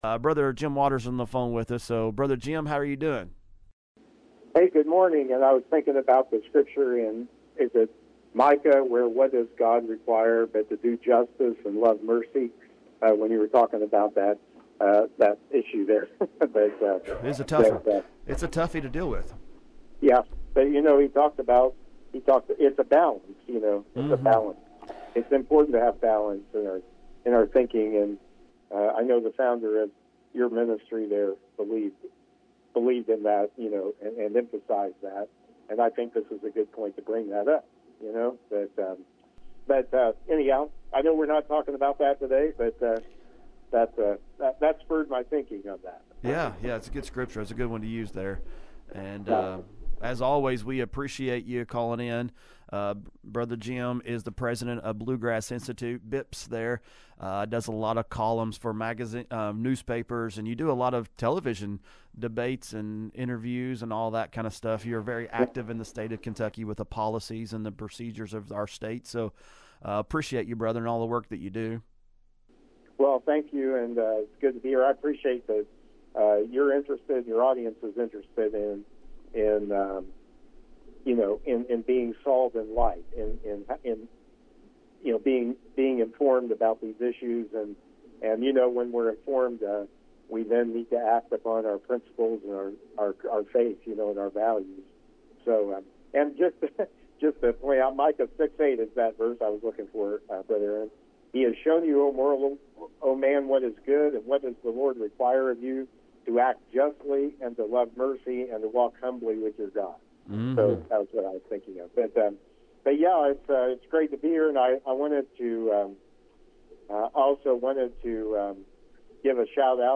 MBR Interview